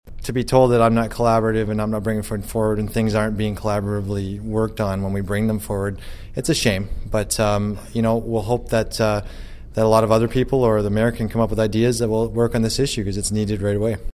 Following the meeting he spoke to Quinte News.